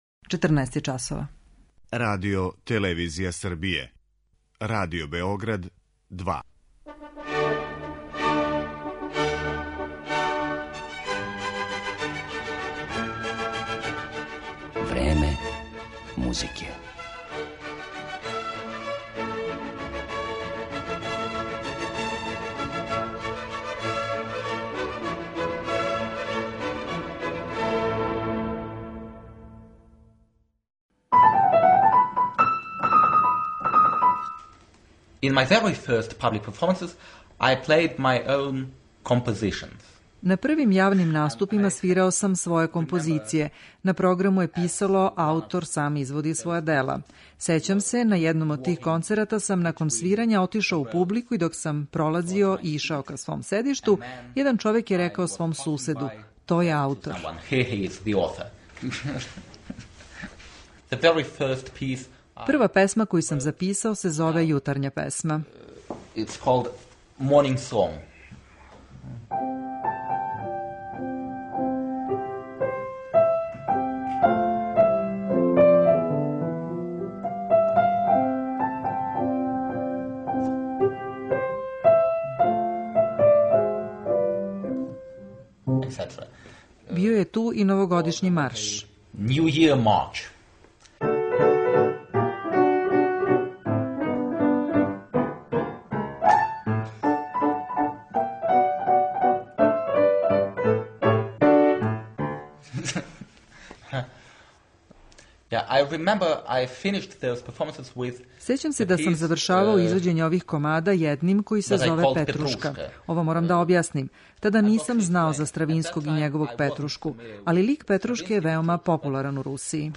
Слушаћете Јевгенија Кисина
Овог славног руског пијанистиу моћи ћете да слушате како изводи композиције Фредерика Шопена, Јоханеса Брамса, Роберта Шумана, Лудвига ван Бетовена и Петра Иљича Чајковског.